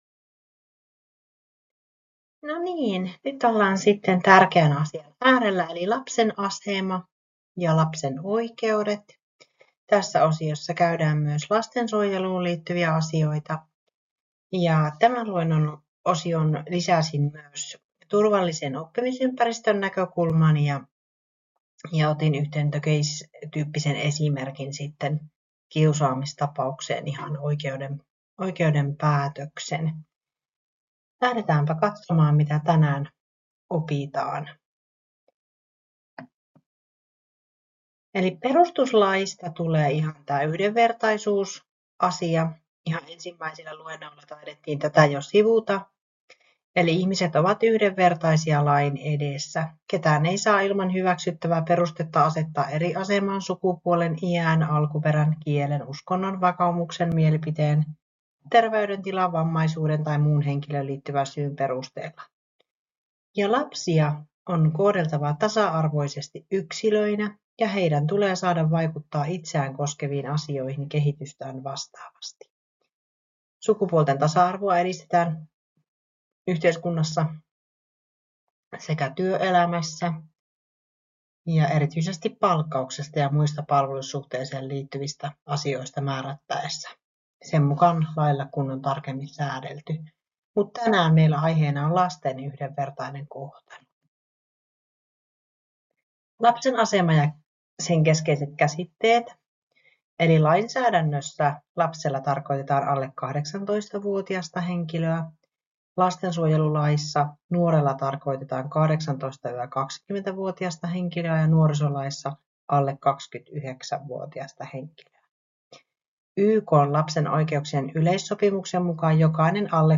KTK0006 Opetushallinnon luentotallenne